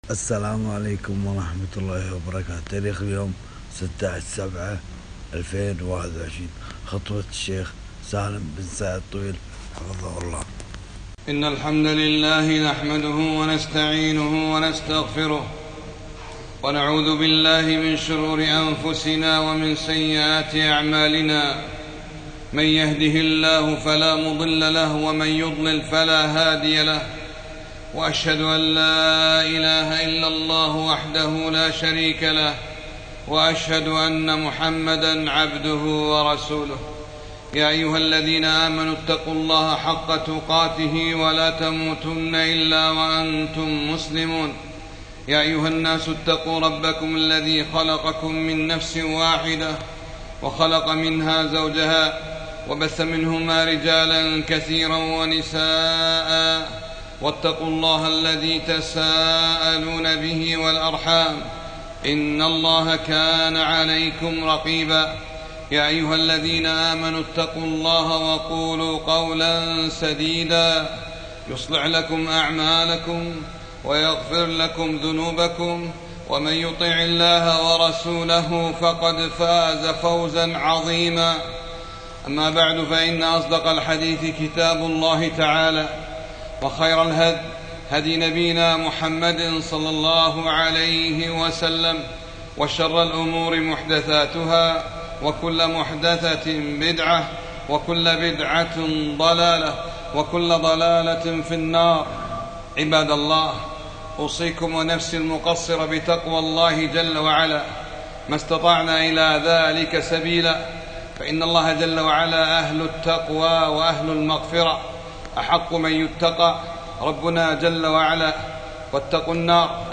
خطبة - كفى بالموت واعظا ياعباد الله